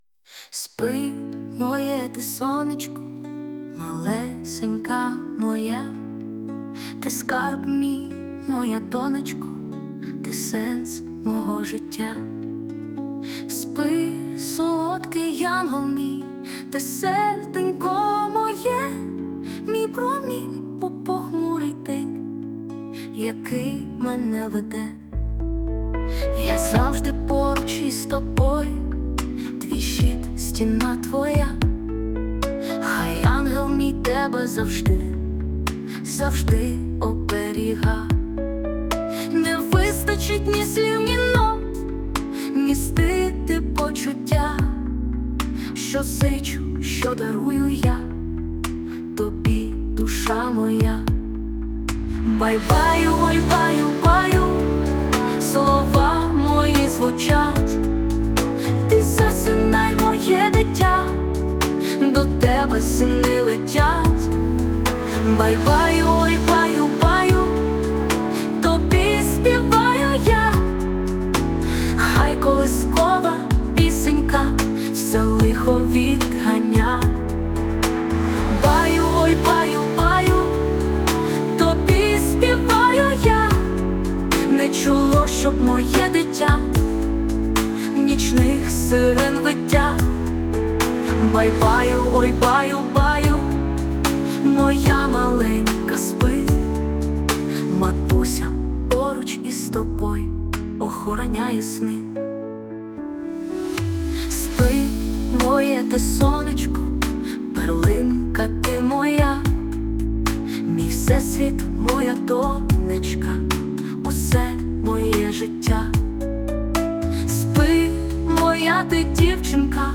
ТИП: Пісня
СТИЛЬОВІ ЖАНРИ: Ліричний
Ніжно, з любов'ю. Гарна колисаночка. 12 12 16 icon_flower